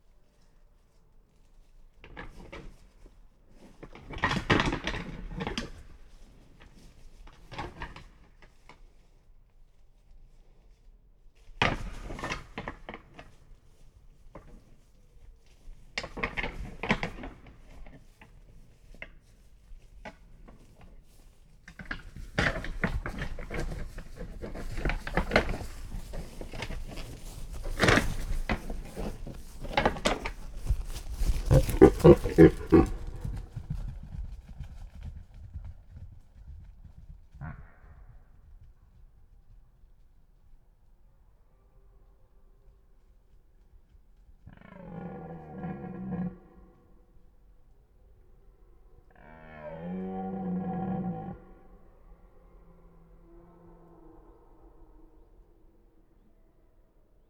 Ce troisième titre de la collection « Galets sonores » regroupe une série d’enregistrements sur le thème du brame du Cerf élaphe, collectés dans différents massifs forestiers de France (Vosges, Loiret, Lozère...) entre 2011 et 2023.